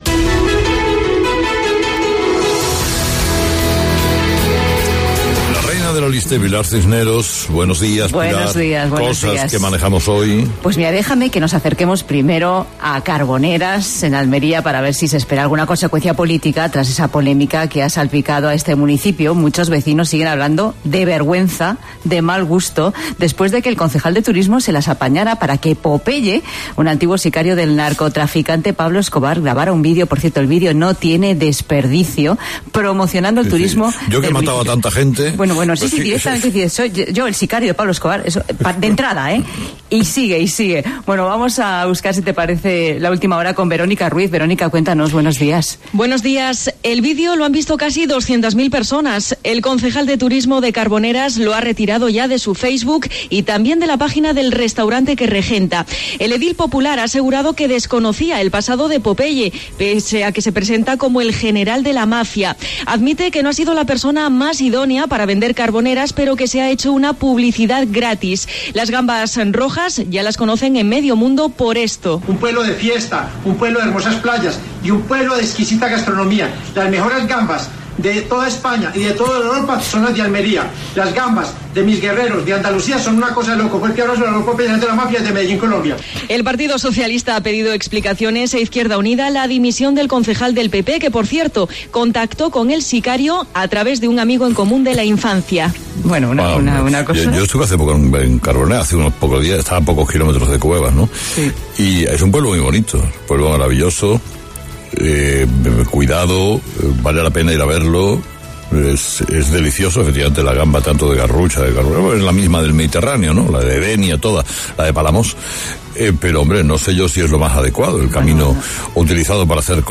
Gregorio, que ha realizado estas manifestaciones en la Romería de la Virgen del Valle de Toledo, tras asegurar que los agentes también presentan contusiones, ha indicado que el detenido se encuentra en la Comandancia de la Guardia Civil de Illescas.